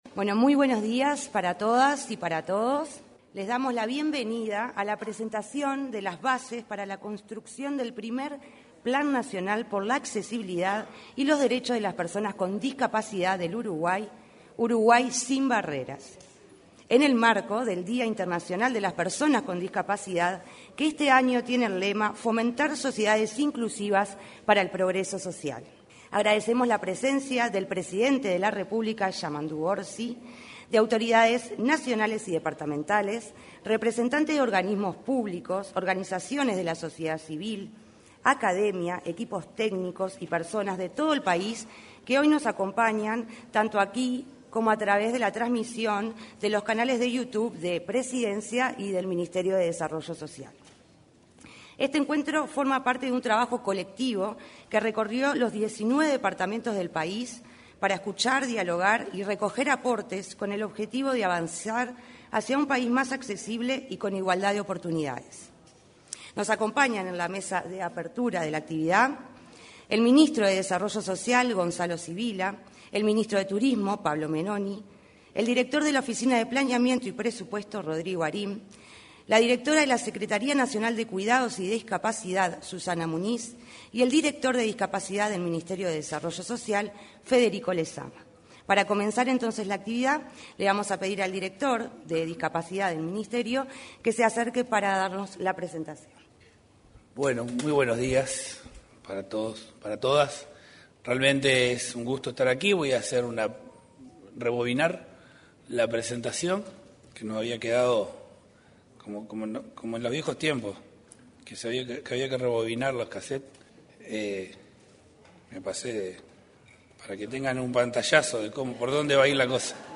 Presentación de bases para construcción del Plan Nacional por la Accesibilidad y Derechos de Personas con Discapacidad 03/12/2025 Compartir Facebook X Copiar enlace WhatsApp LinkedIn En el auditorio de la Torre Ejecutiva se realizó la presentación de las bases para la construcción del Plan Nacional por la Accesibilidad y Derechos de Personas con Discapacidad. En la ocasión, se expresaron el director de Discapacidad del Ministerio de Desarrollo Social, Federico Lezama; la directora de Sistema Nacional de Cuidados y Discapacidad, Susana Muniz; el ministro de Turismo, Pablo Menoni; el director de la Oficina de Planeamiento y Presupuesto, Rodrigo Arim, y el ministro de Desarrollo Social, Gonzalo Civila.